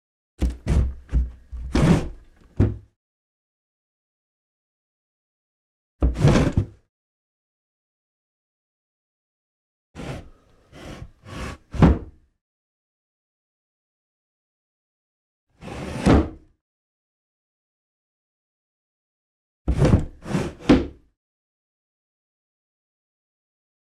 household
Old Window in Sticky Wooden Frame Slide Open